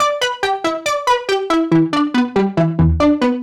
Index of /musicradar/french-house-chillout-samples/140bpm/Instruments
FHC_Arp A_140-E.wav